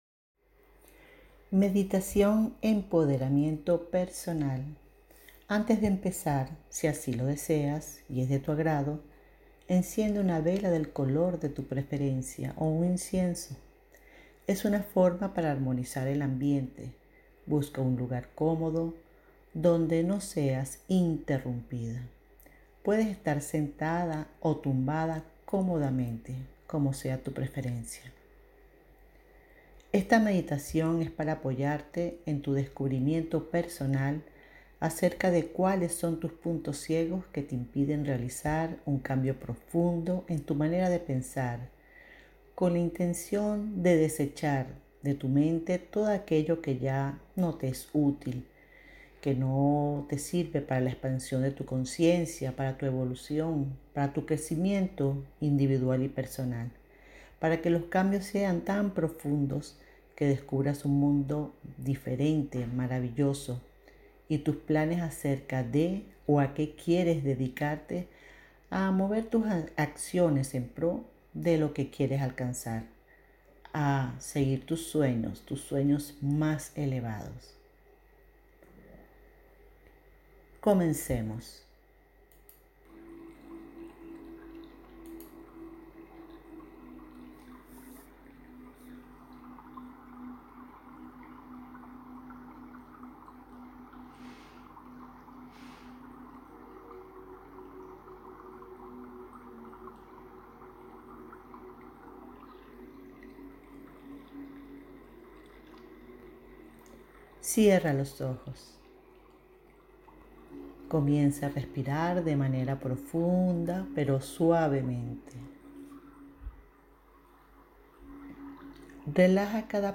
Meditación: "Empoderamiento Personal"